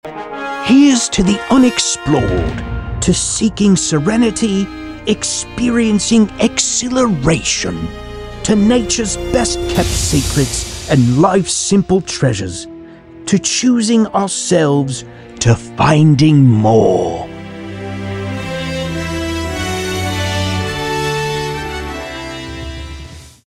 Attenborough delivery for a British travel commercial
British Travel Commercial Promo.mp3